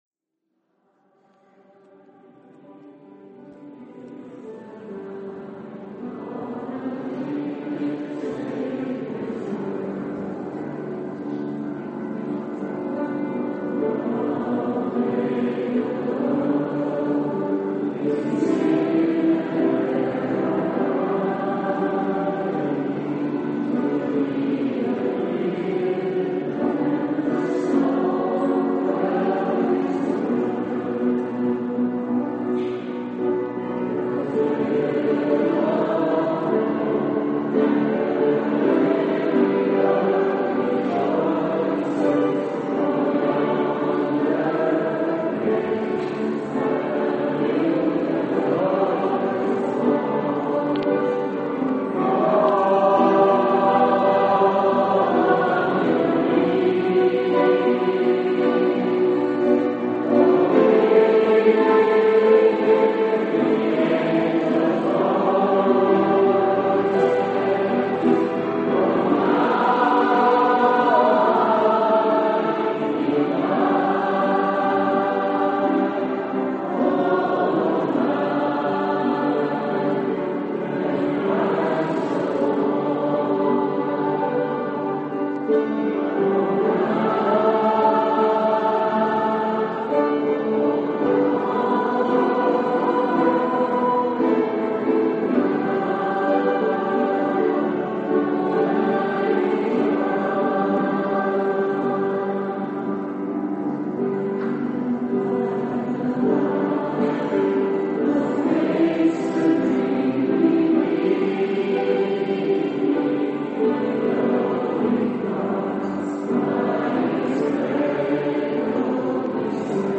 Christ Church in Jeruzalem, de oudste protestantse kerk in het Midden-Oosten.